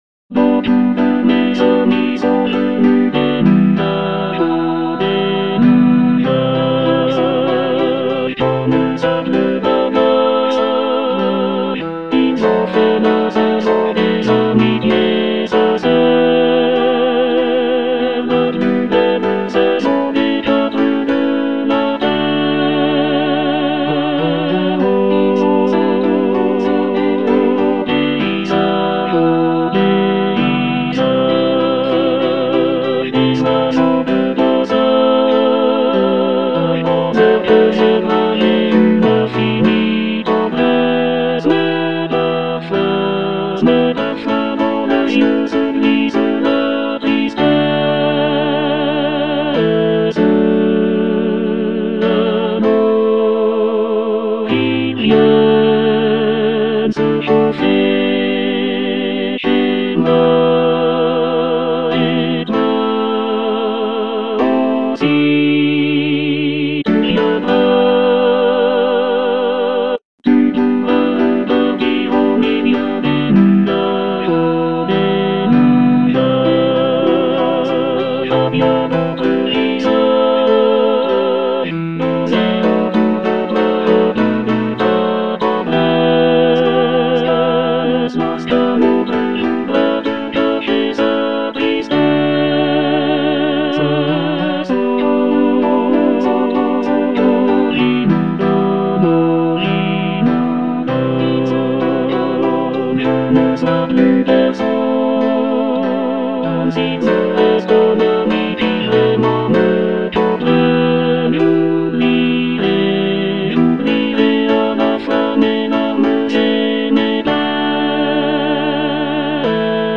Tenor I (Emphasised voice and other voices)
piece for choir